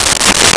Toilet Paper Dispenser 02
Toilet Paper Dispenser 02.wav